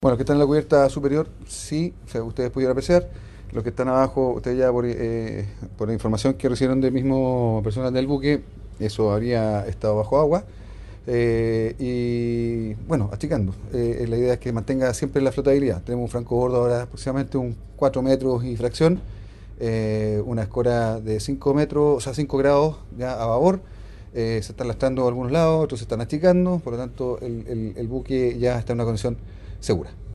10-GOBERNADOR-MARITIMO-AYSEN-2.mp3